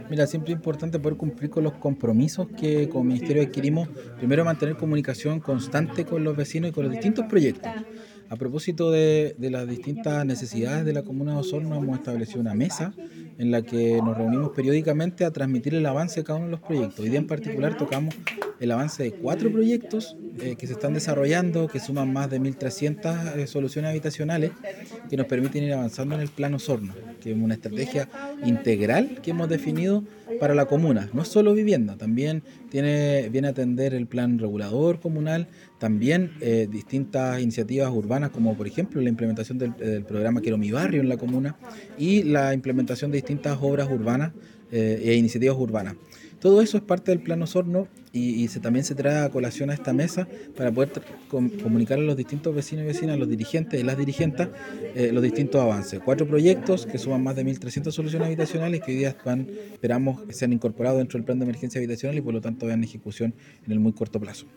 El Seremi de Vivienda y Urbanismo, Fabián Nail indicó que es muy importante cumplir con los compromisos que se tomaron con la comunidad, además de reiterar que es fundamental poder comunicarle a los vecinos y vecinas acerca de los avances que se van dando.